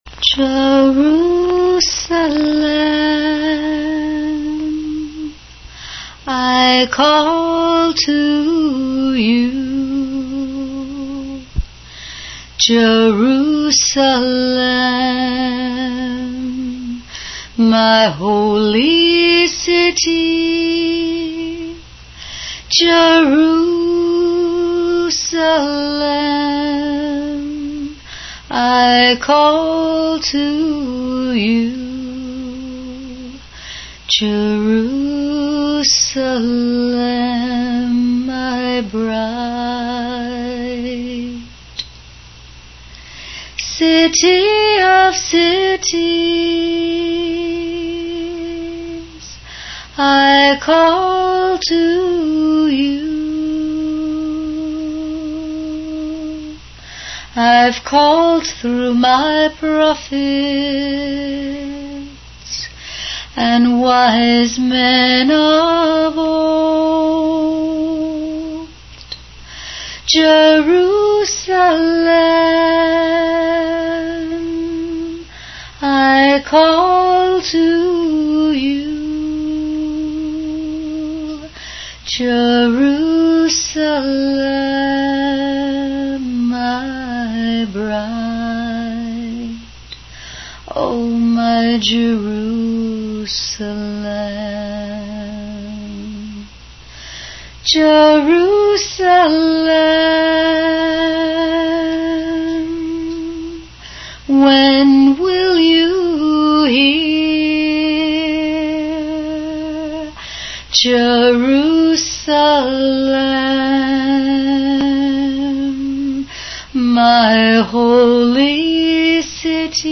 singing "Jerusalem, Jerusalem